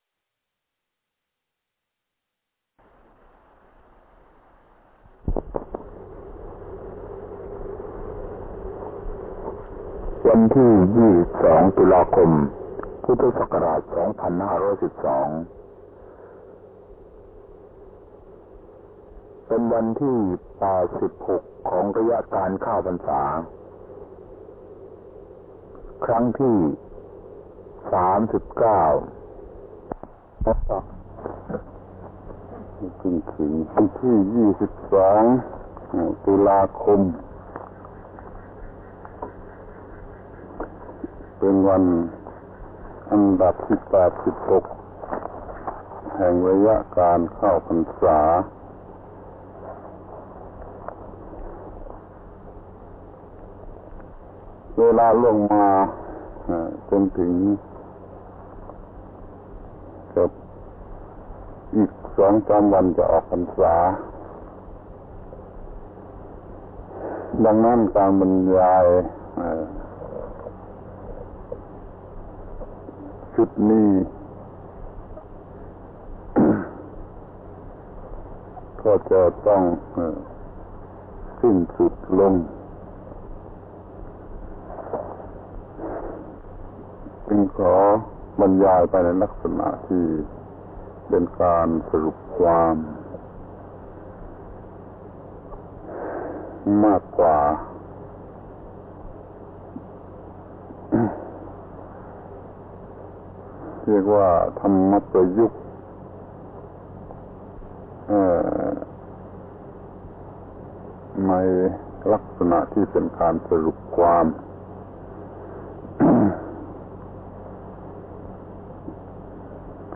อบรมพระนวกะในพรรษา ปี 2512 ครั้ง 39 ธรรมประยุกต์ในลักษณะสรุปความ